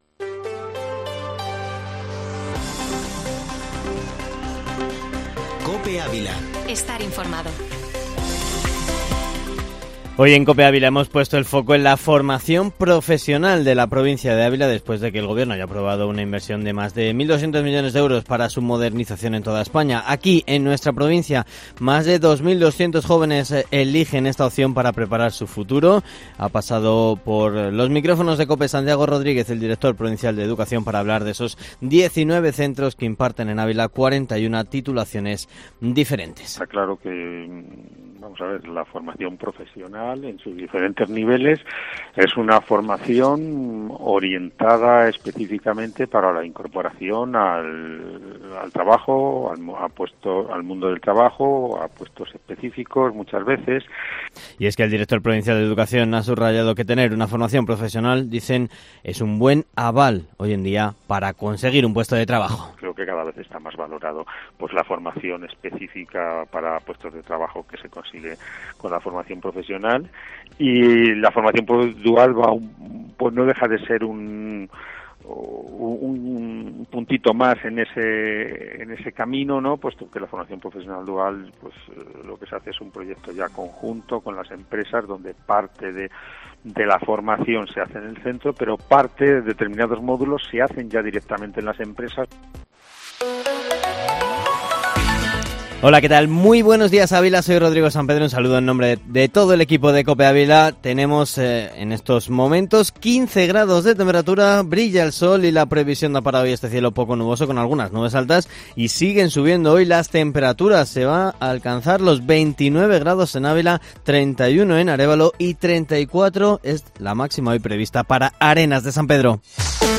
Informativo Matinal Herrera en COPE Ávila -19-mayo